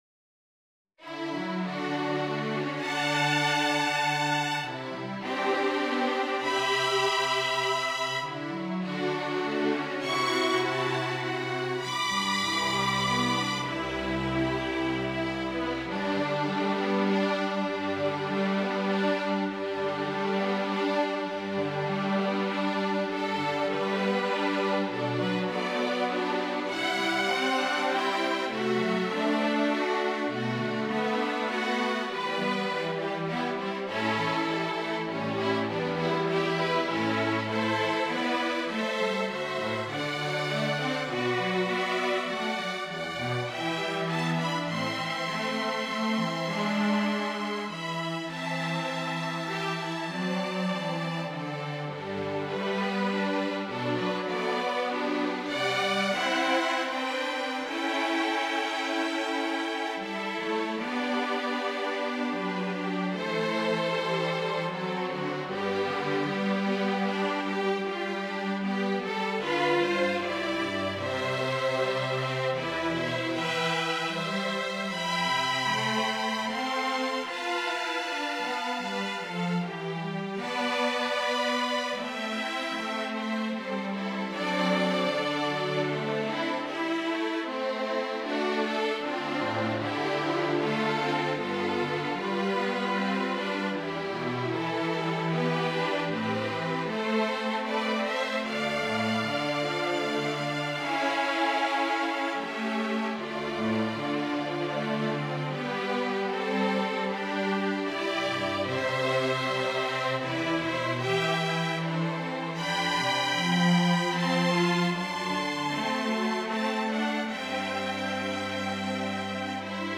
Hollywood and Broadway String Quartet